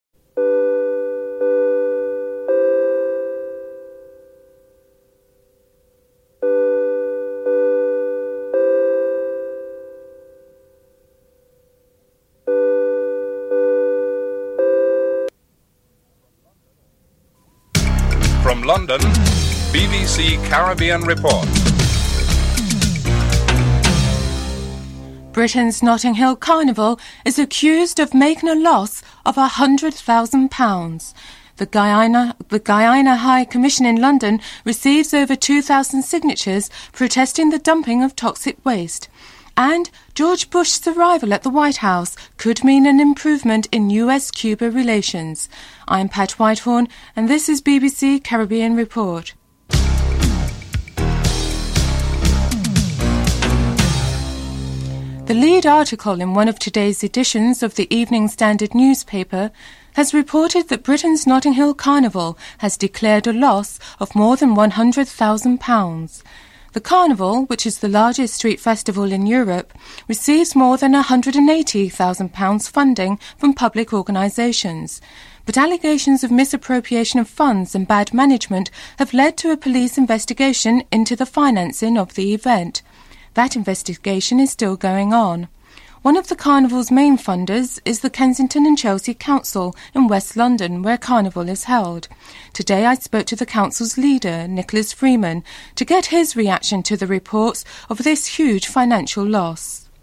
1. Headlines (00:00-00:53)
3. Interview with actrees, Carmen Munroe who delivered a petition to the Guyana's High Commission in London on the dumping of toxic wastes in Guyana (03:48-07:13)